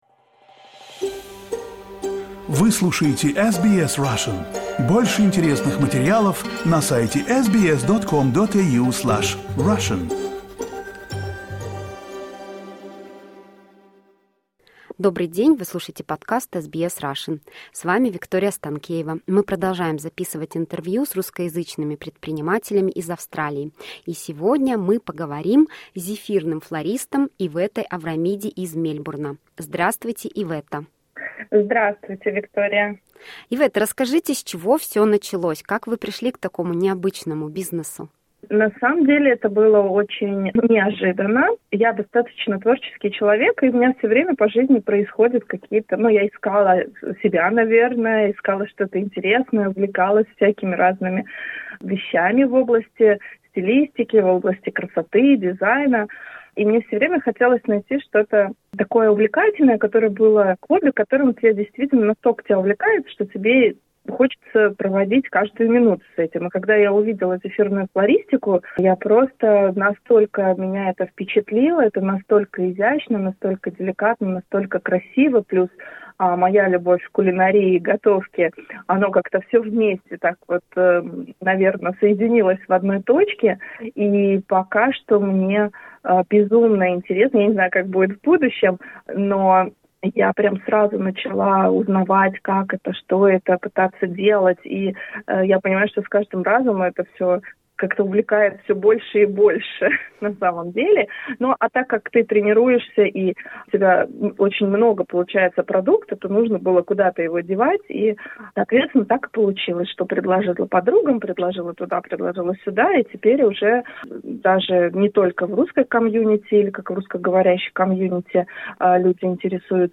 В интервью